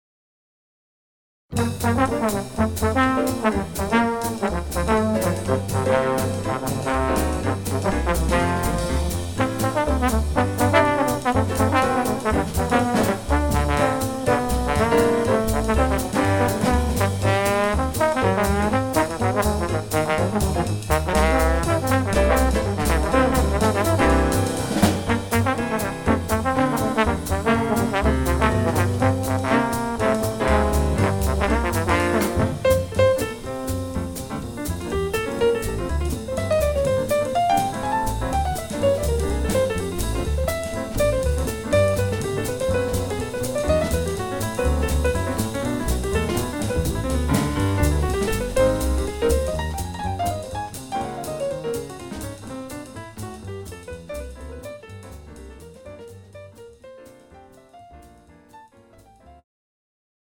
The Best In British Jazz
Recorded at Norden Farm Centre for the Arts, Jan 9th 2014